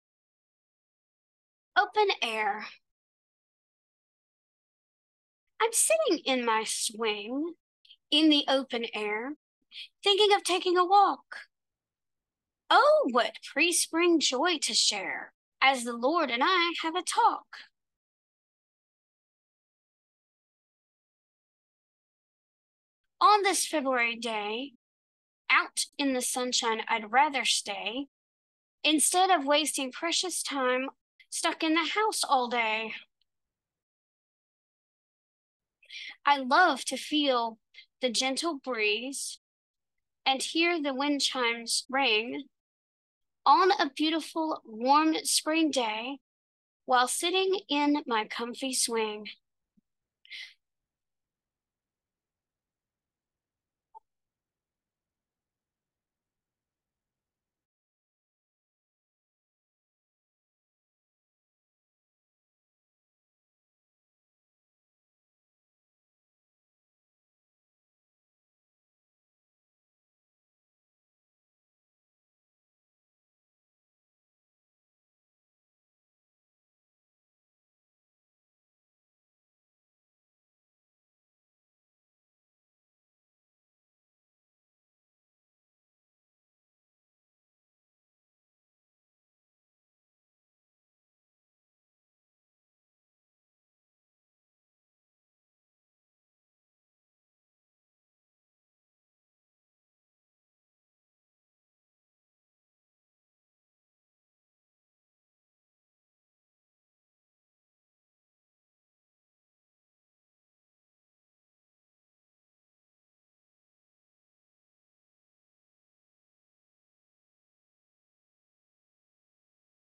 As I listened to my first recording, I found that some of the lines (thinking of taking a walk, for example) had a swinging, sing-songy rhythm that was totally unintentional!